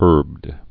(ûrbd, hûrbd)